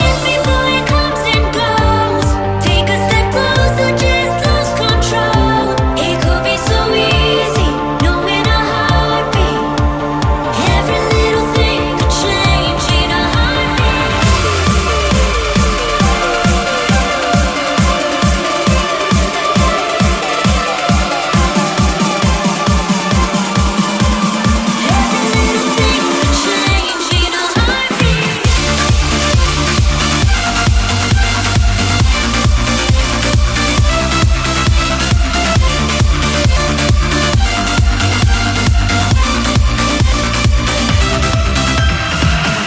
Genere: dance, edm, club, remix